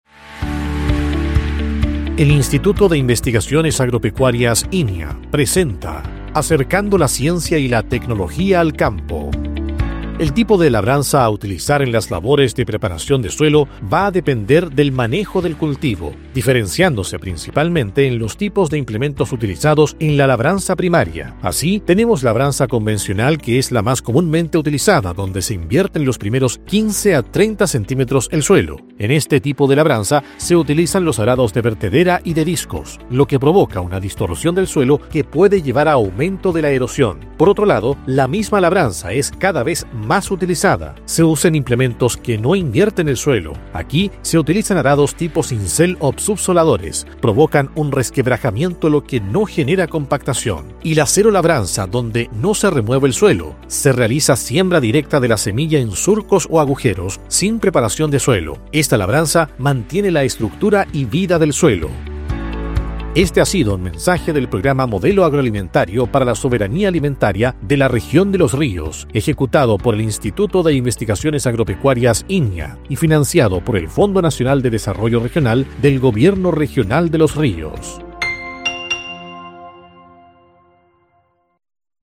Cápsula Radial INIA Remehue